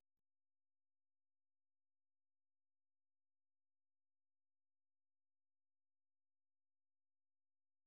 Allegro molto vivace. Лирический танец на народную тему.